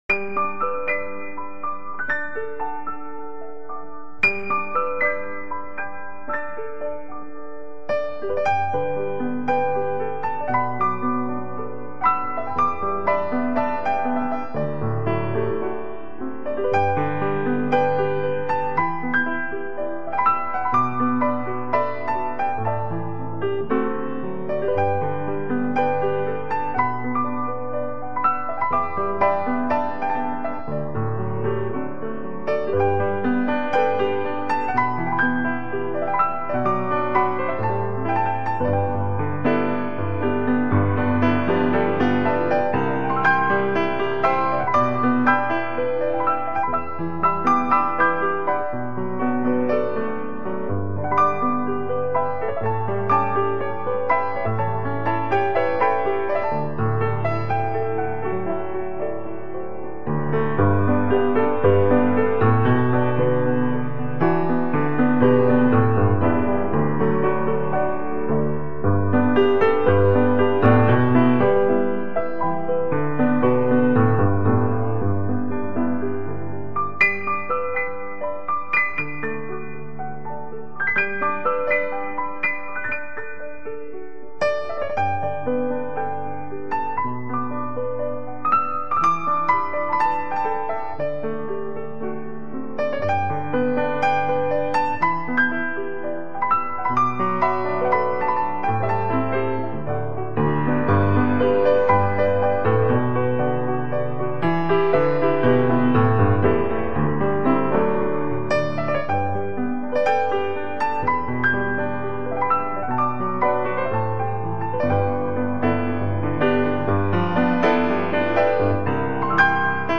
感觉慢悠悠的没有什么钢琴的'钢'的锐味在哪....慢吞吞的听啊听啊得都等不及了.......好可惜..